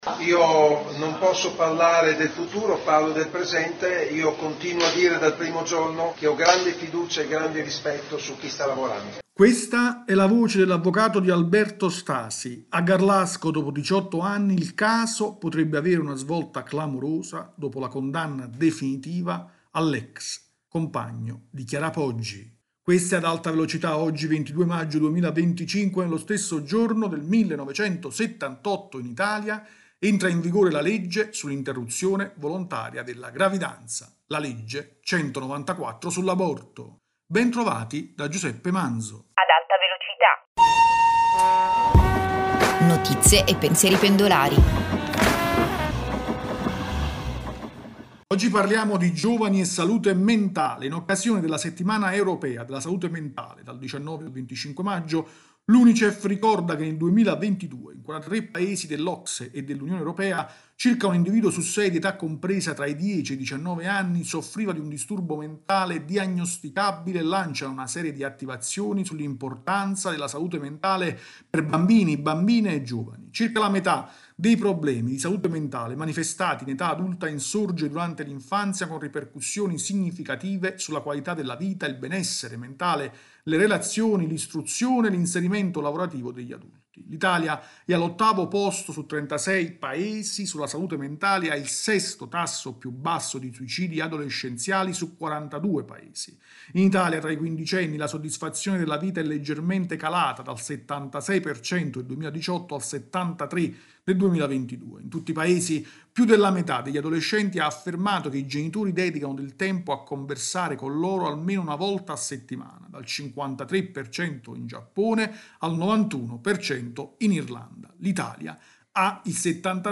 rubrica quotidiana